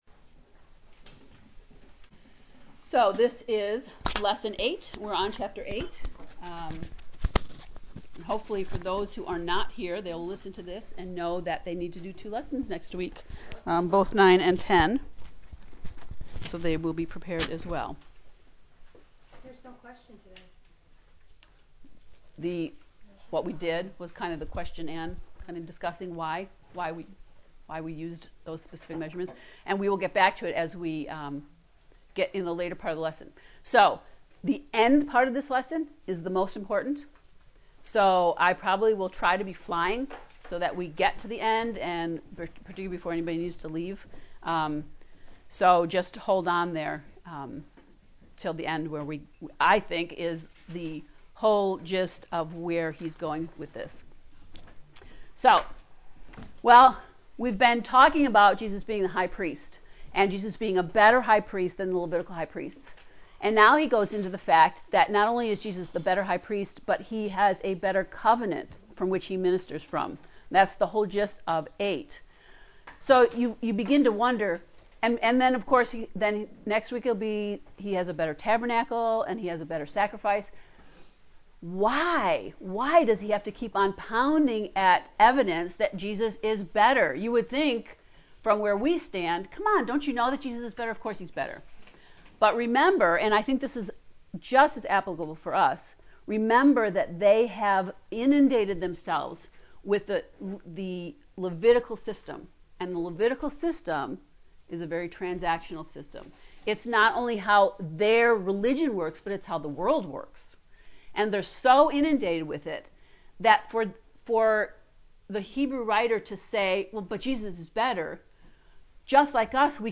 To listen to the lecture for lesson 8 “A Better Covenant” please click below:
heb-ii-lecture-8.mp3